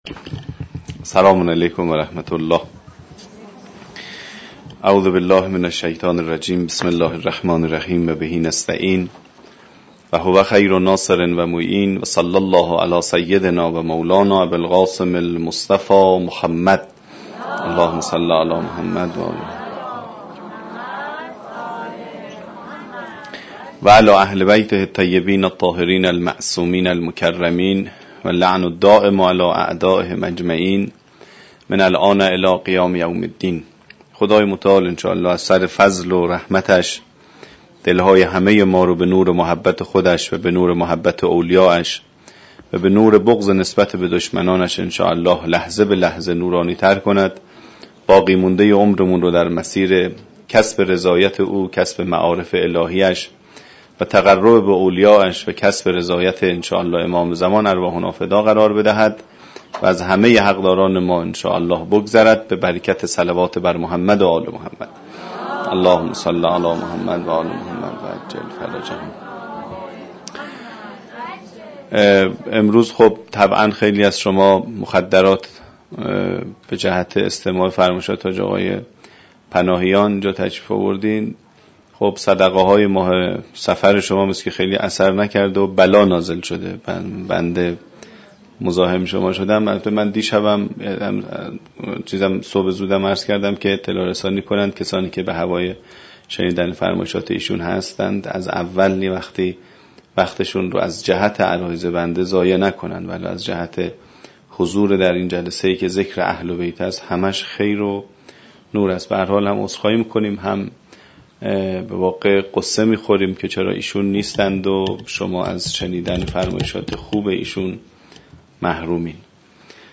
مراسم سوگواری ختم المرسلین و امام حسن مجتبی (ع) - (93/9/30)